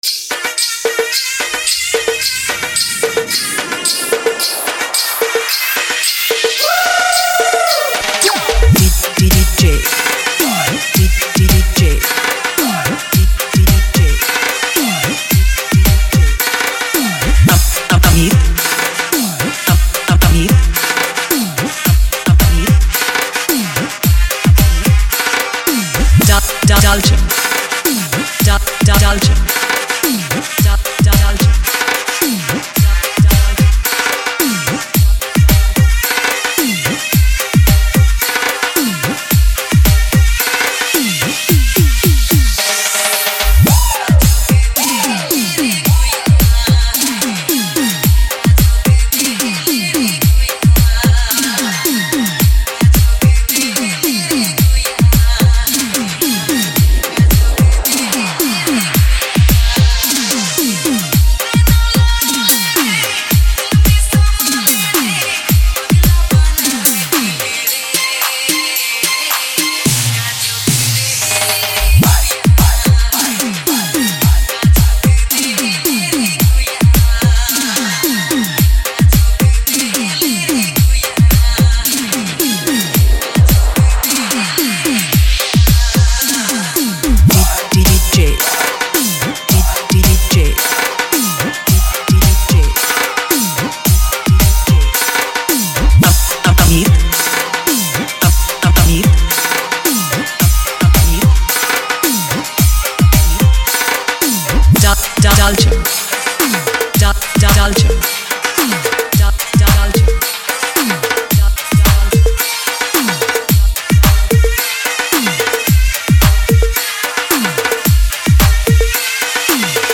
a high-energy Nagpuri procession beat mix
This track is perfect for celebrations and festive vibes.
electrifying beats